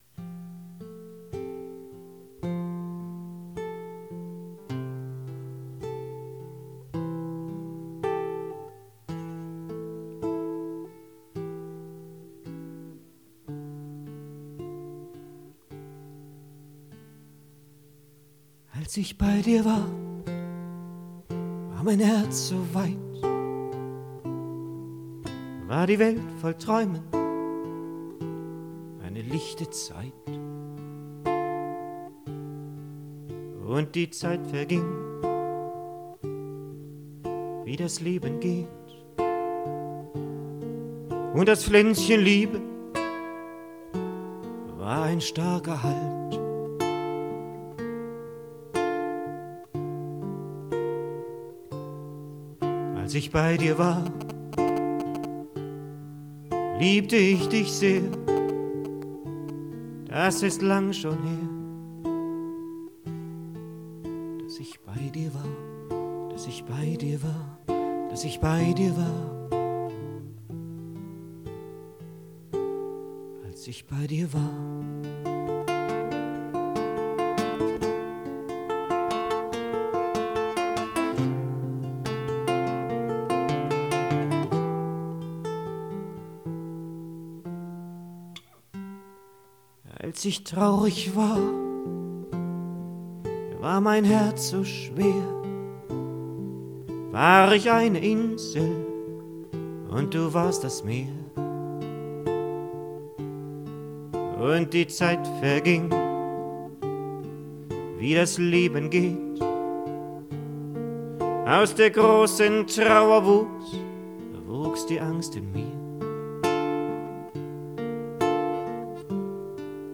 ohne technische effekte als „pilotspur“ für proben mit dem